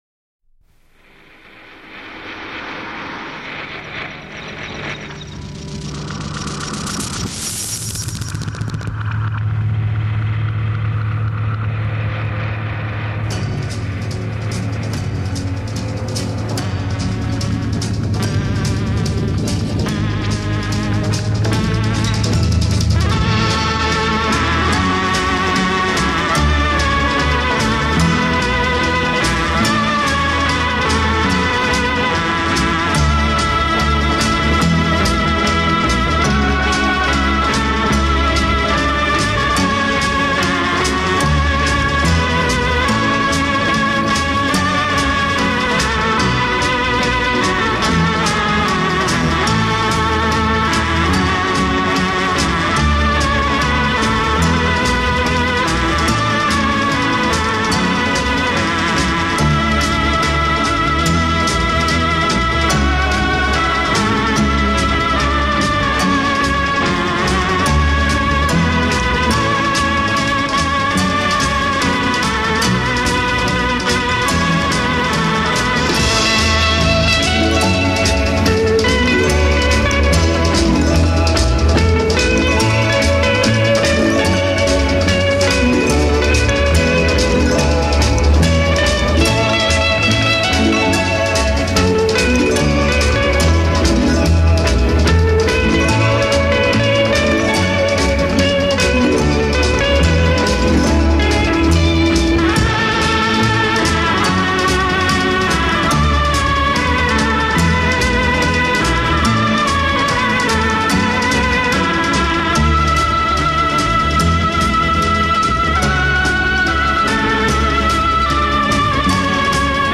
A verse : 16 principal theme (A major) played by organ
bridge : 2 upward chromatic modulation from A to D
16 principal theme with male voices